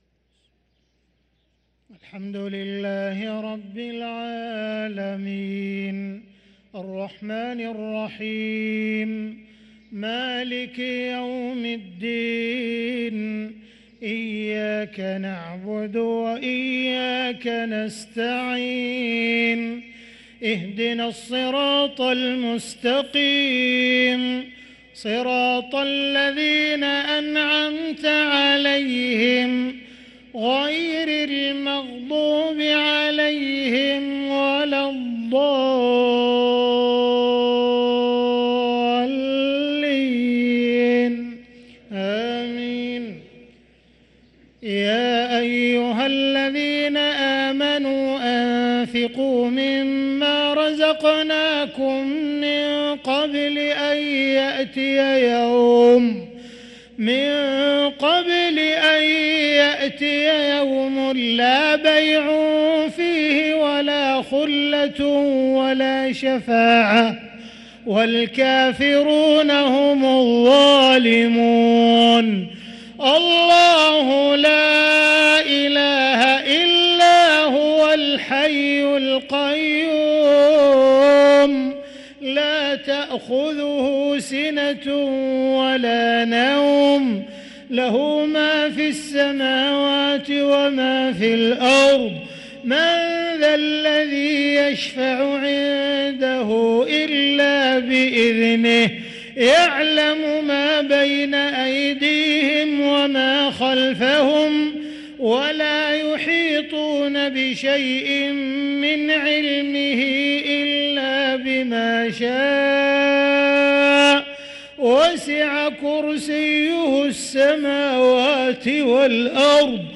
صلاة العشاء للقارئ عبدالرحمن السديس 6 جمادي الآخر 1445 هـ
تِلَاوَات الْحَرَمَيْن .